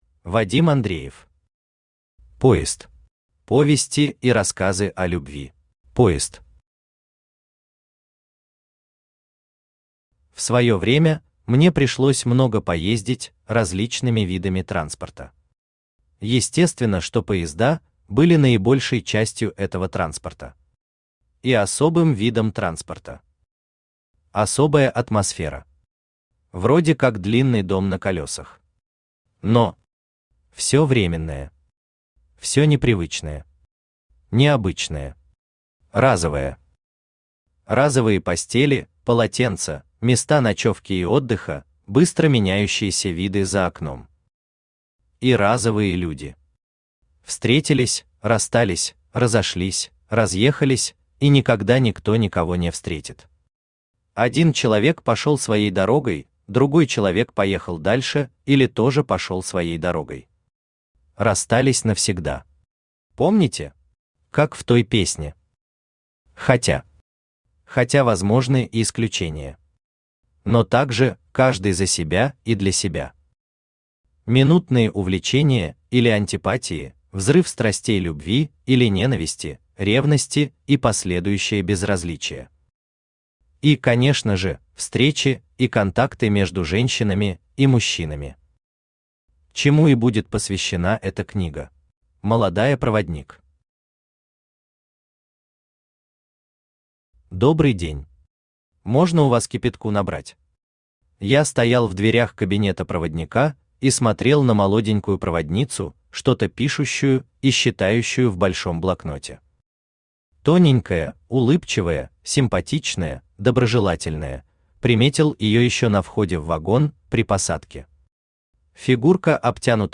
Аудиокнига Поезд. Повести и рассказы о любви | Библиотека аудиокниг
Aудиокнига Поезд. Повести и рассказы о любви Автор Вадим Андреев Читает аудиокнигу Авточтец ЛитРес.